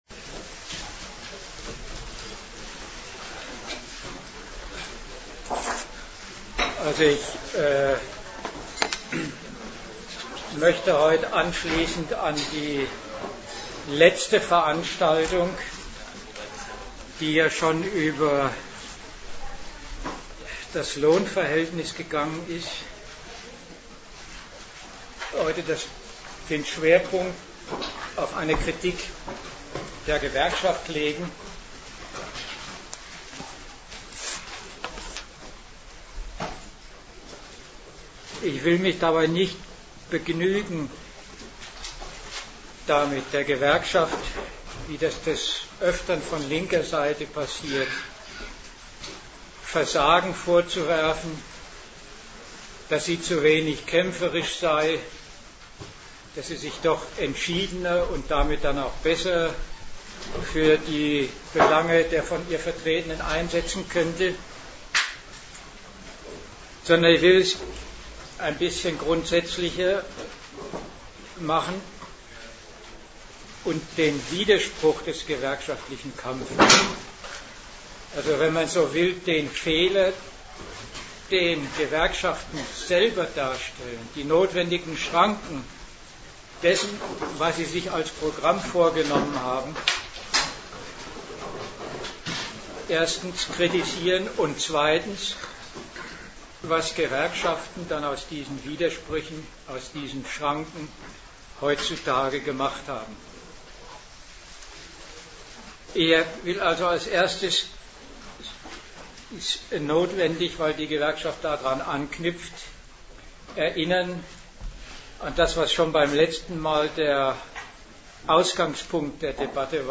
Datum 13.12.2007 Ort München Themenbereich Arbeit, Kapital und Sozialstaat Veranstalter AK Gegenargumente Dozent Gastreferenten der Zeitschrift GegenStandpunkt Gliederung des Vortrags: Teil 1: Die aktuellen Lohnarbeitszustände.